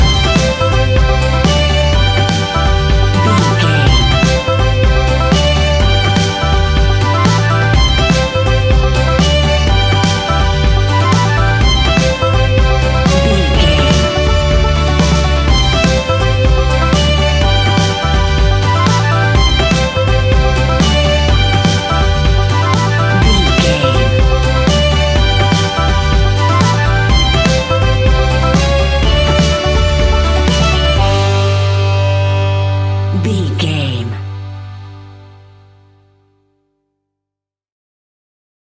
Ionian/Major
Fast
acoustic guitar
mandolin
ukulele
lapsteel
drums
double bass
accordion